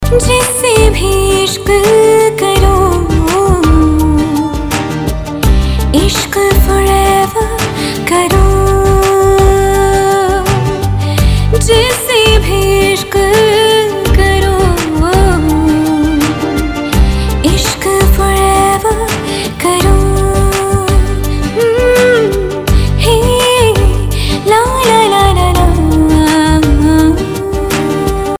Female Ringtone.mp3 Song Download Bollywood Mazafree